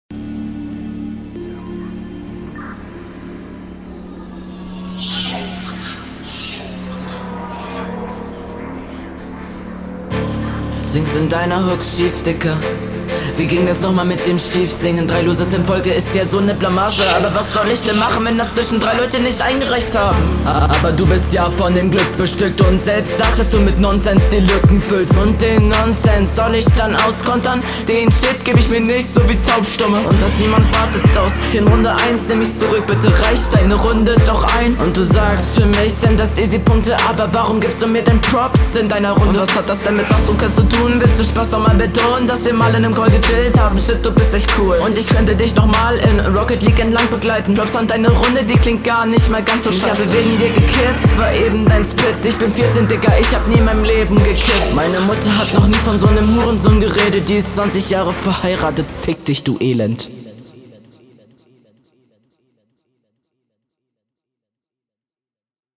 geil Einstieg brettert schon und man merkt deine Überlegenheit in Sachen Sound:) Text: wie gehabt …
Flow: Flowlich ist das ganze schon ein Stückchen anspruchsvoller und gekonnter, aber 1-2 Stellen waren …